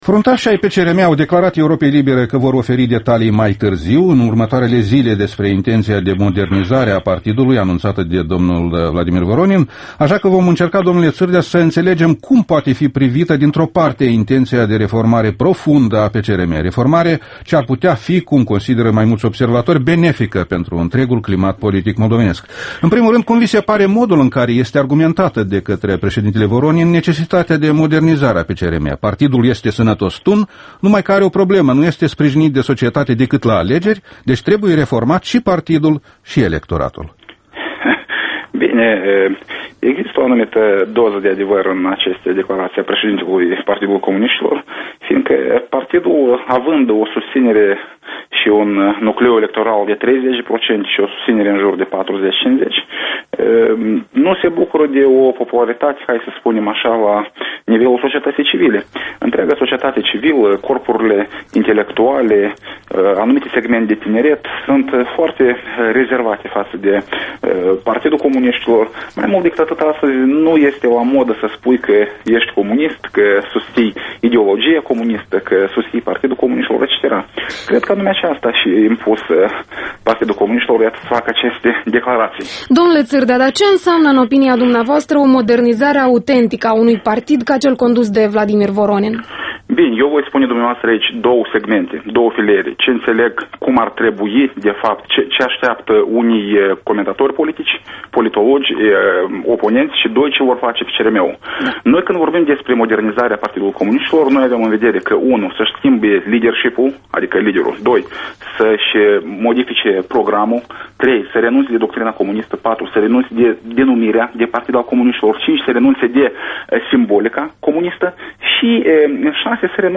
Interviul matinal EL: cu Bogdan Țîrdea